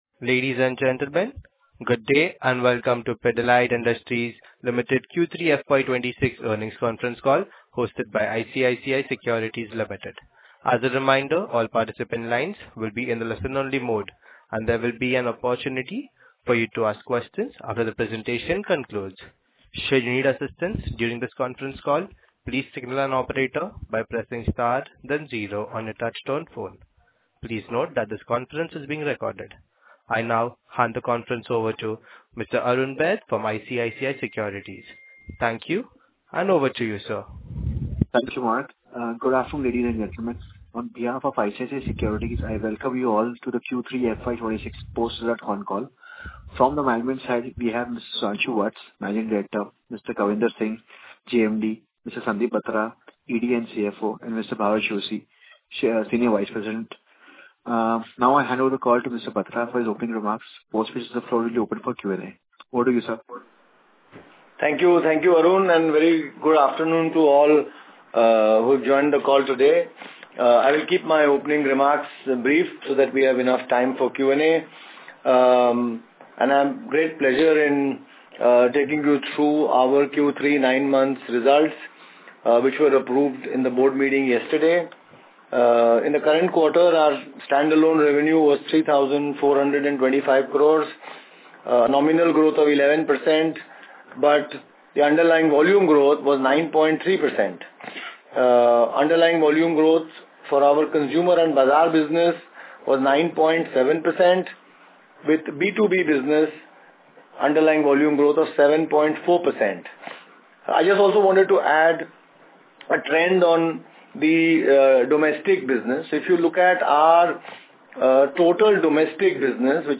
Quarterly Results Download PDF Press Release Download PDF Analyst Meet Invite Download PDF Analyst Meet Presentation Download PDF Analyst Audio Call Recording Download Audio Analyst Audio Call Transcript Download PDF
pidilite-q3fy26-earnings-call.mp3